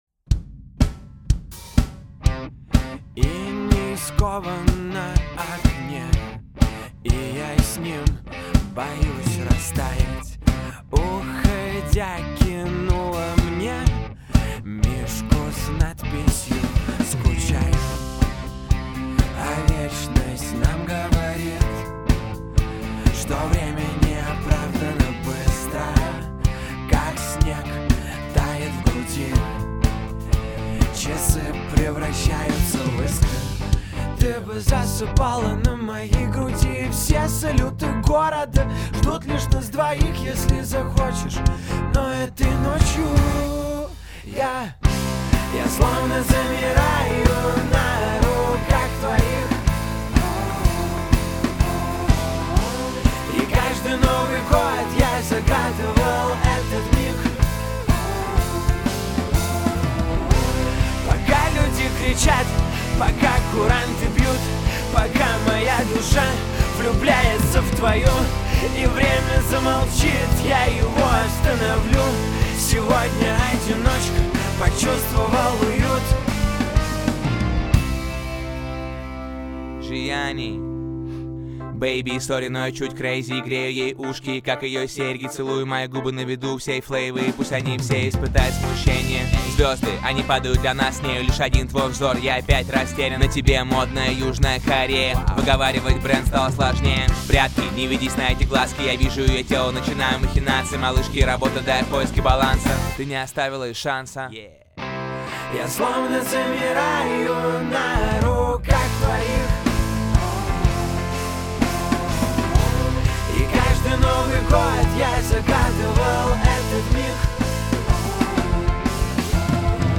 Категория: Рэп